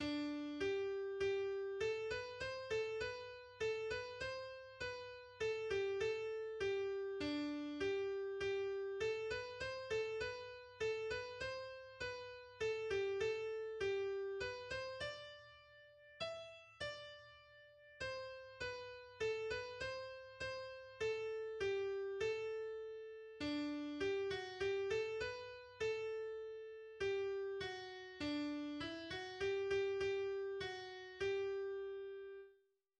Melodia